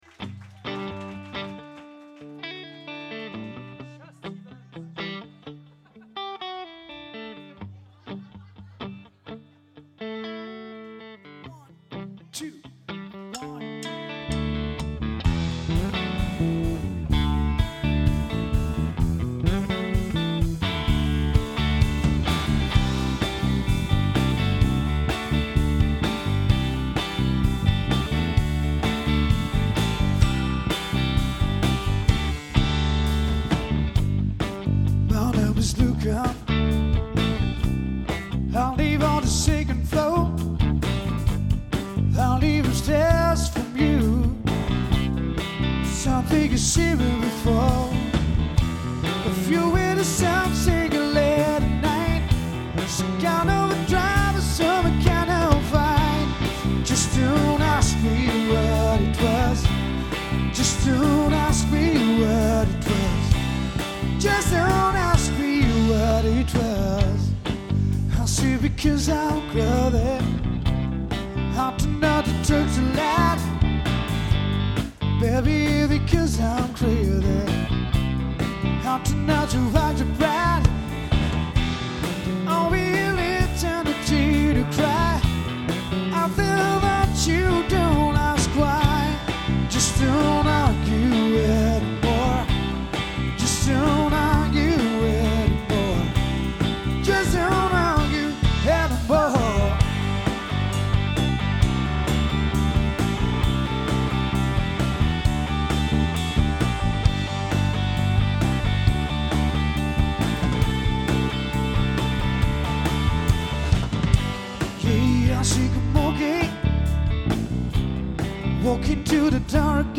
LIVE EN PUBLIC (1983 - 2020) - Un résumé...
Public House (Frejus) 07.09.2018